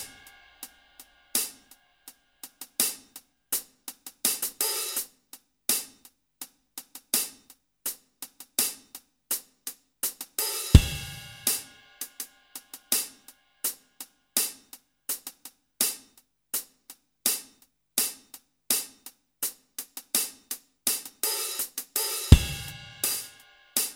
no Piano Pop (1980s) 5:02 Buy £1.50